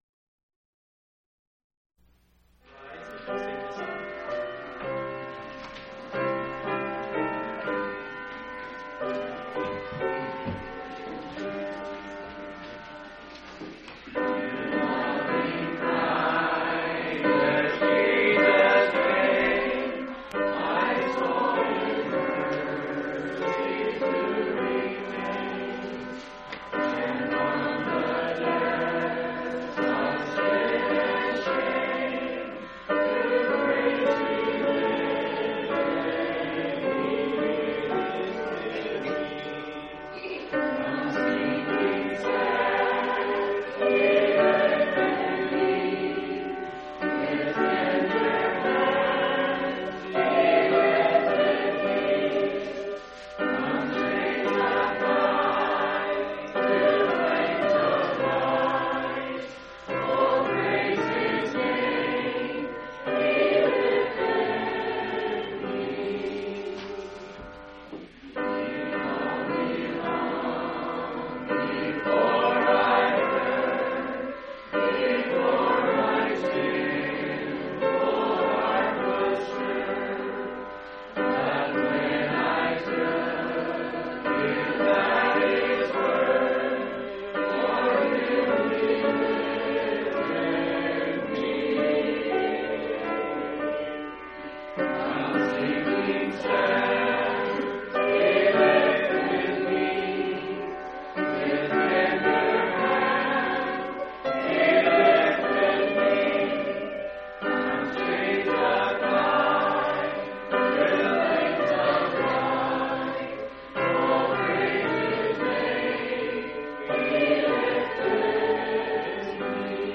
11/26/1993 Location: Phoenix Reunion Event